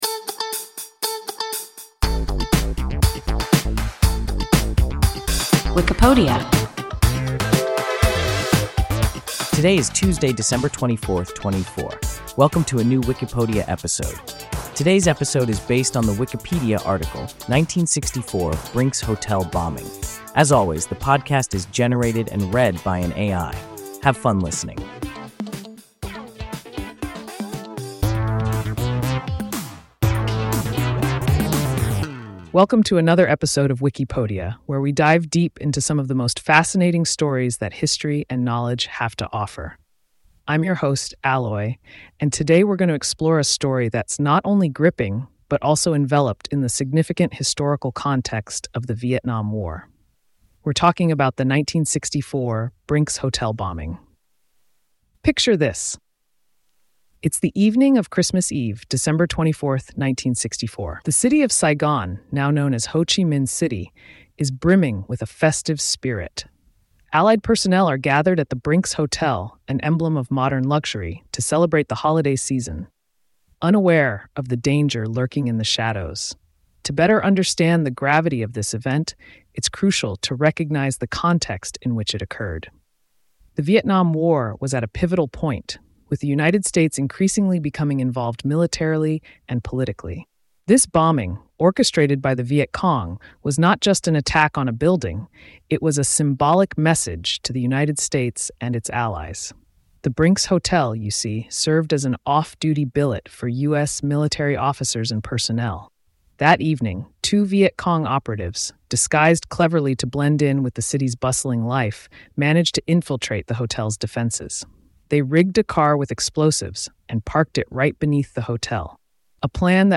1964 Brinks Hotel bombing – WIKIPODIA – ein KI Podcast